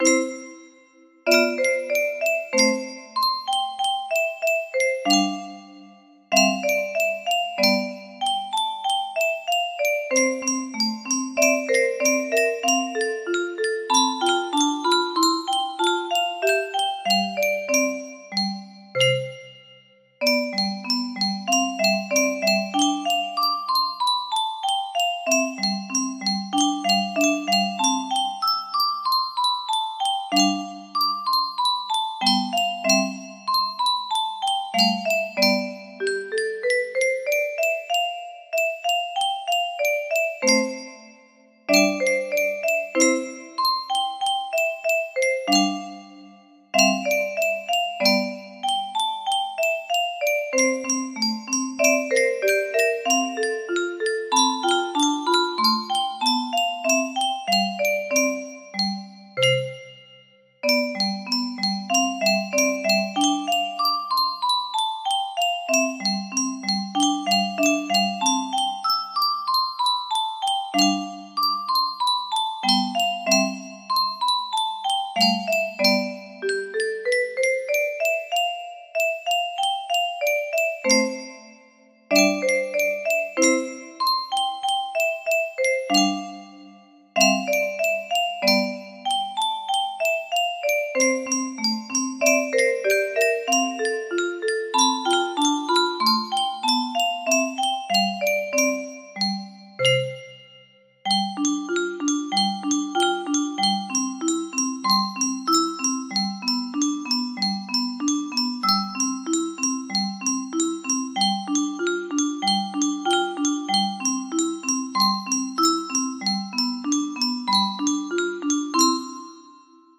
Sonatina n1 op05 music box melody